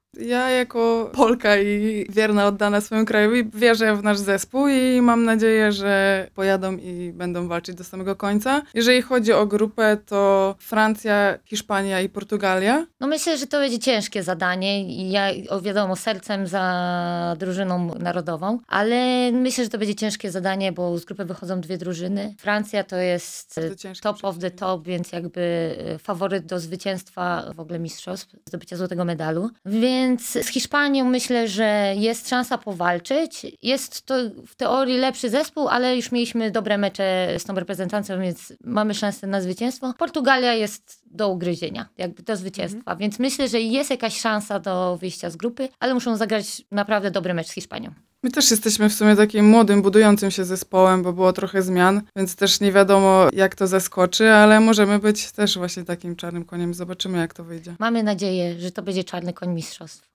Cała rozmowa ze szczypiornistkami w audycji „Sportowy kwadrans” dziś o 15:30!